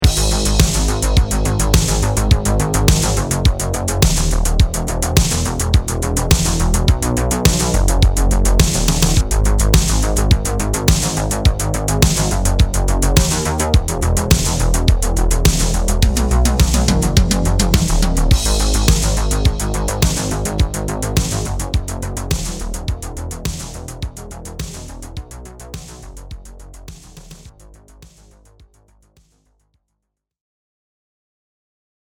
By flipping the left-sided switch from ‘MONO’ to ‘STEREO’ as well as choosing ‘DIMENSION MODE 4’ (which is straight stereo), we get a super wide bass tone in no time.
The Bus plugin now gives our bass more consistency and also a tiny bit of grit thanks to the saturation.
And this is how it sounds like with DDD – Dimension Chorus and Bus engaged:
DDD-Bus-80s-Synthwave.mp3